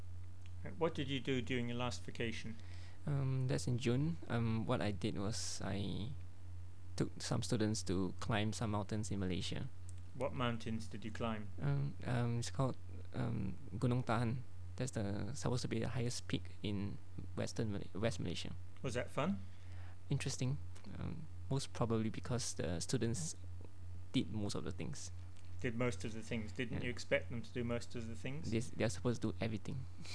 The NIE Corpus of Spoken Singapore English
Male Speaker 12